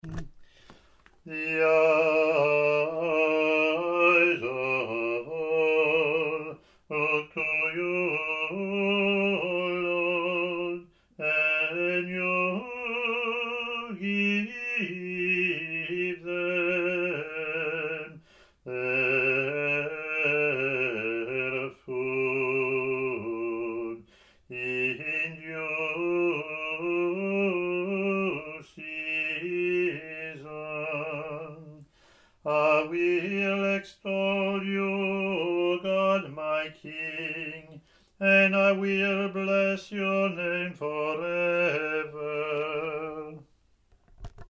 English antiphon – English verseYear A Latin antiphon + verses,